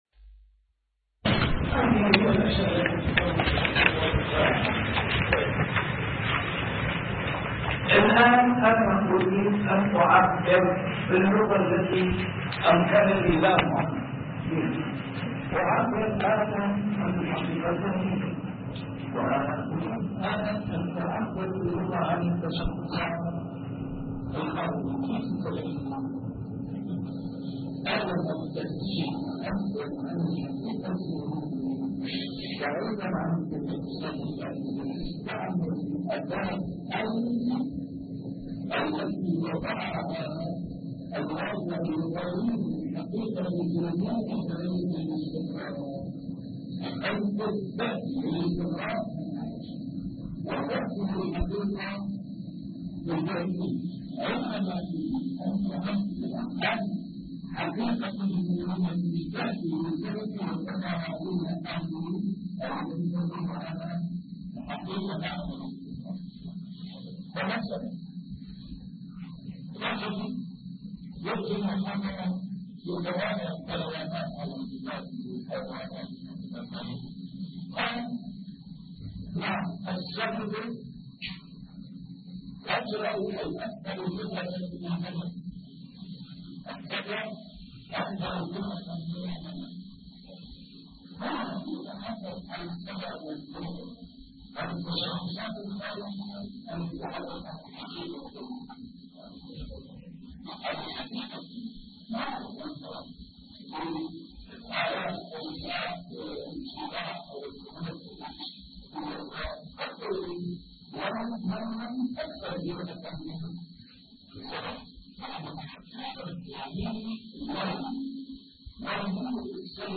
الدرس الرابع عشر: دلالات الألفاظ من حيث درجة الشمول ونوعه فيها [اللفظ المطلق واللفظ العام]